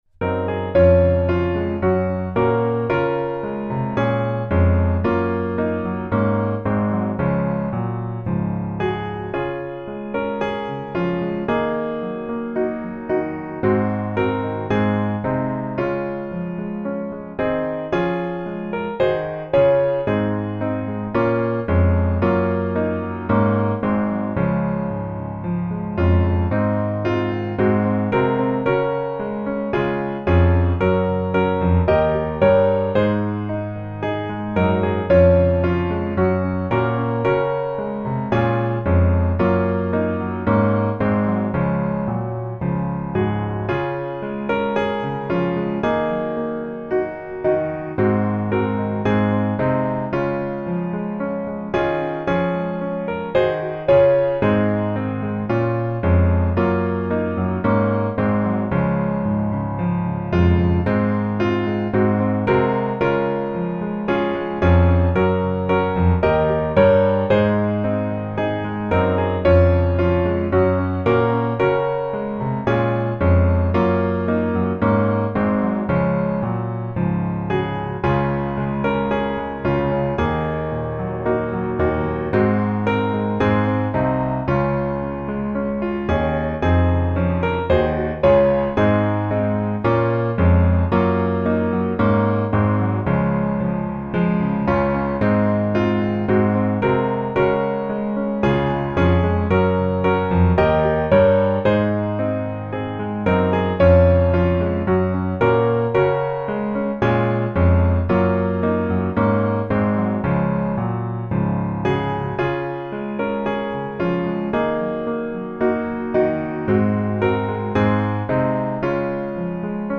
Db Majeur